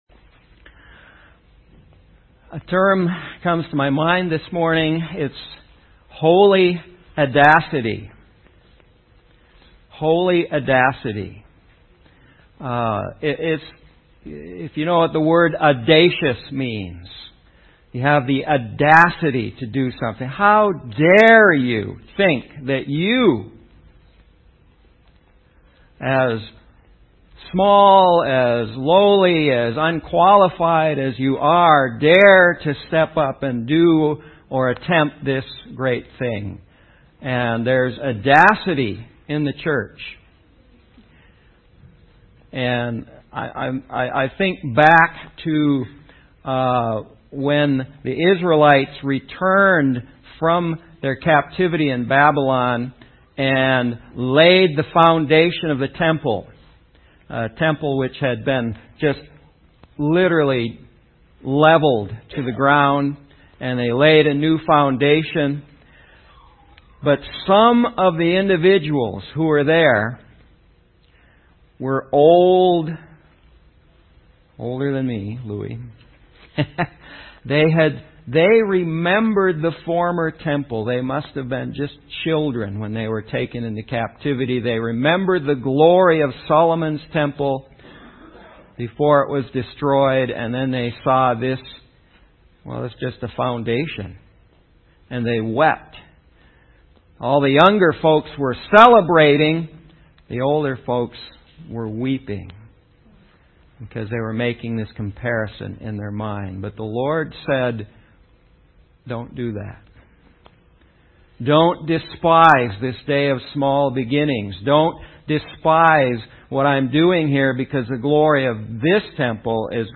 The Kingdom This entry was posted in sermons .